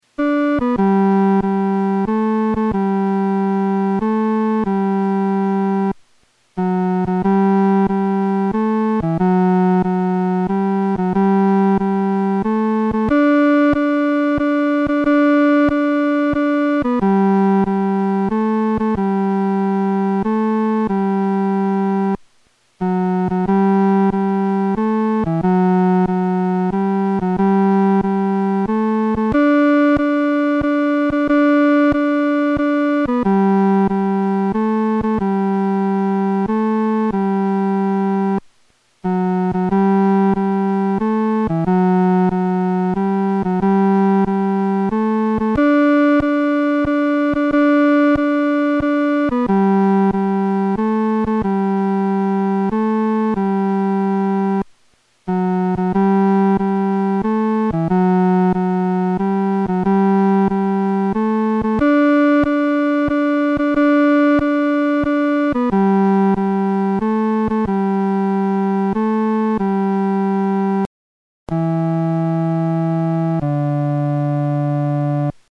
男高